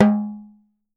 08 TALKING D.wav